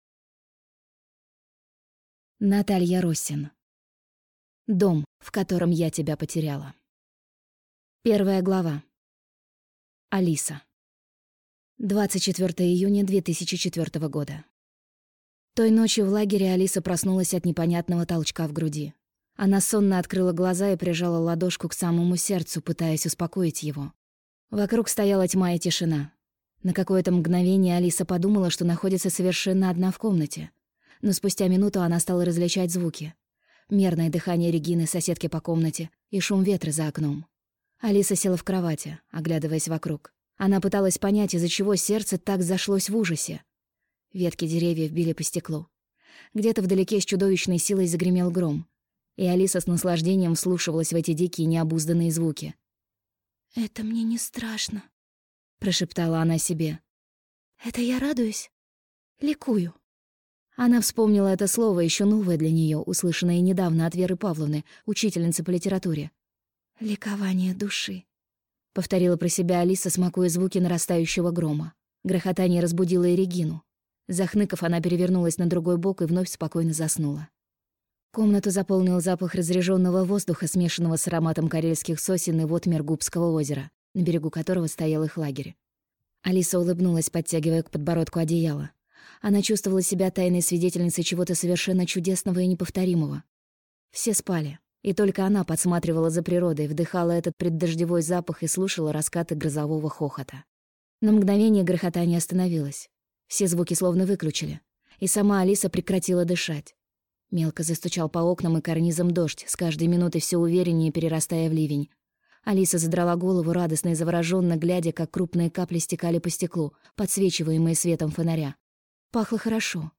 Аудиокнига Дом, в котором я тебя потеряла | Библиотека аудиокниг